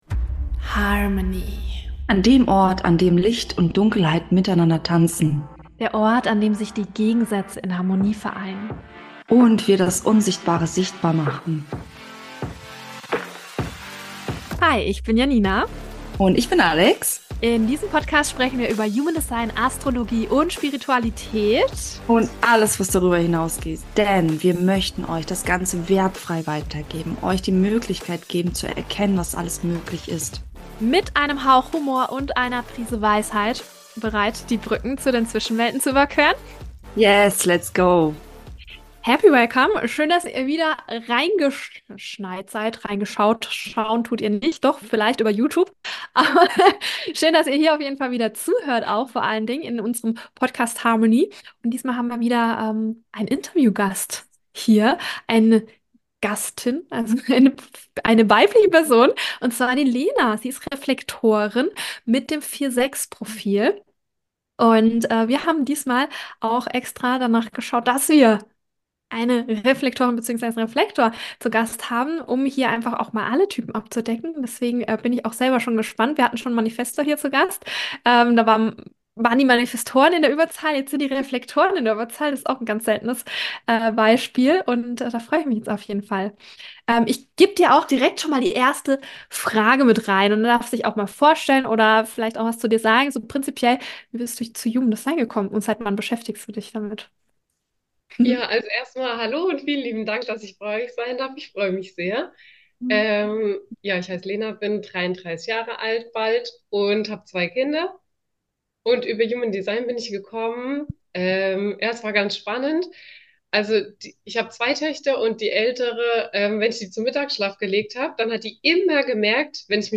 Reflektor Talk mit einer Reflektorin 4/6 - Entscheidungen & Reflektorleben ~ Human Design Astrologie - Podcast